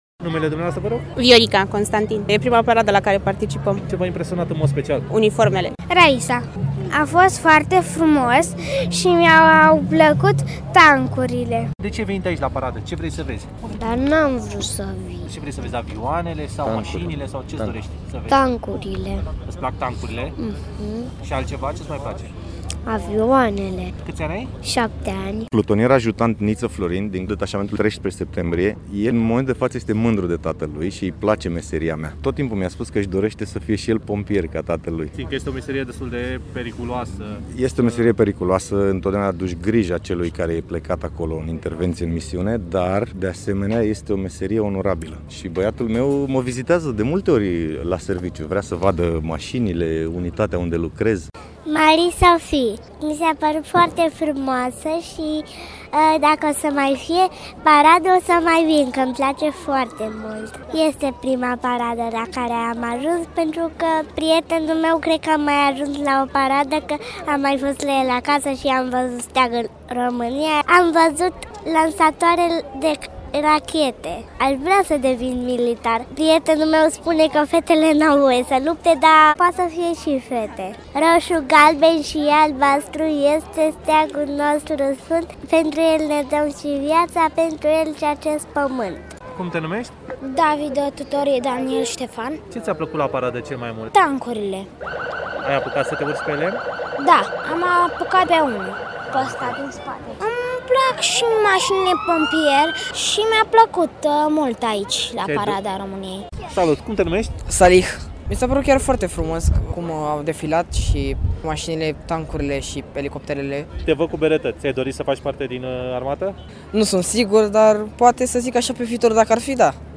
La final, oamenii au avut ocazia să se fotografieze cu militarii prezenți la expoziția de tehnică militară și au oferit câteva opinii în legătura cu parada organizată în acest an.
VOX-1-decembrie.mp3